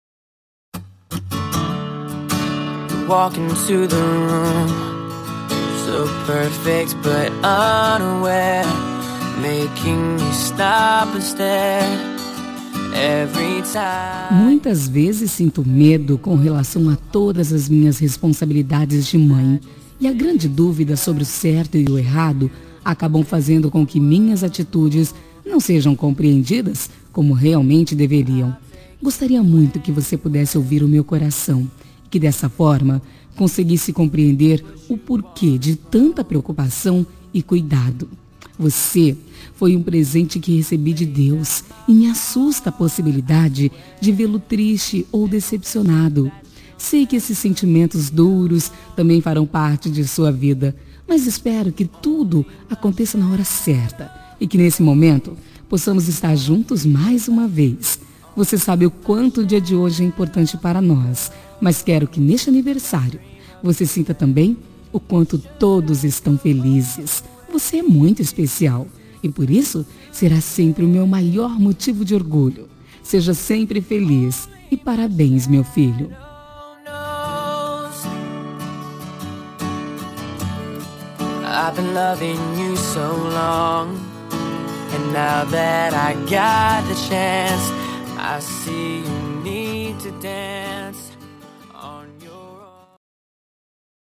Telemensagem de Aniversário de Filho – Voz Feminina – Cód: 1816